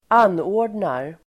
Uttal: [²'an:å:r_dnar]